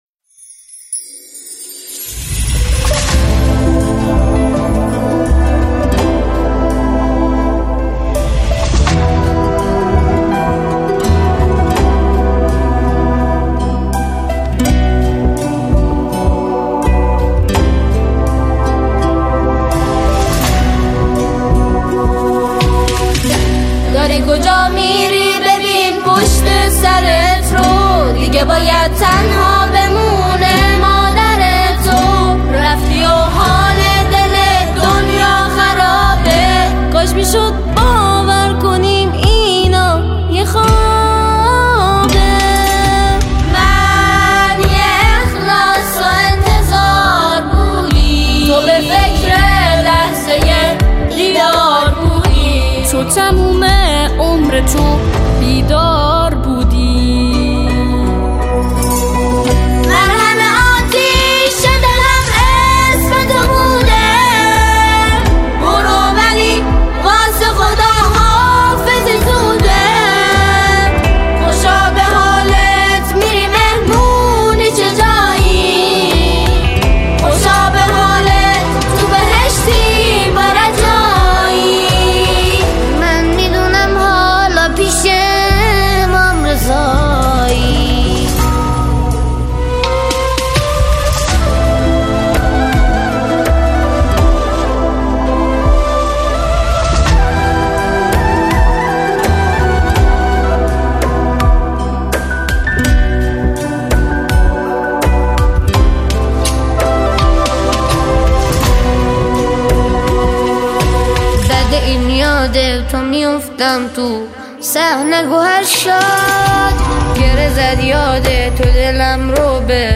سرود شهید رئیسی
همخوانی